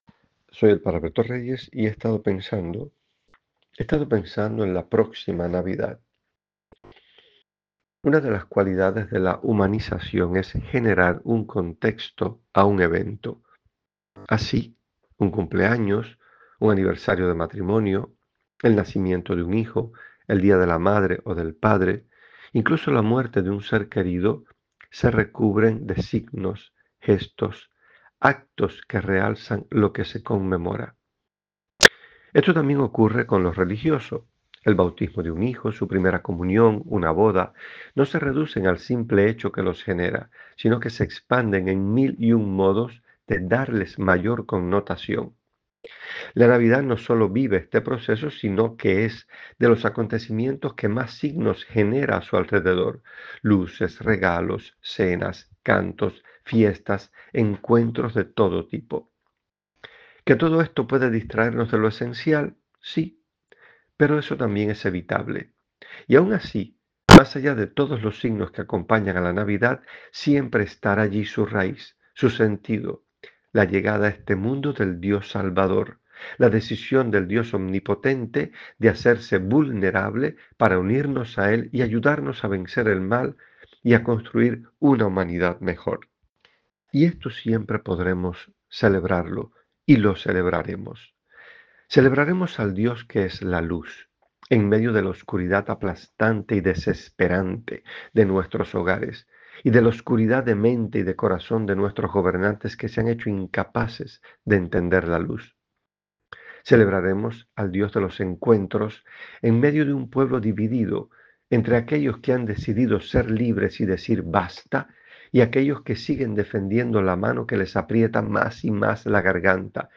En su voz: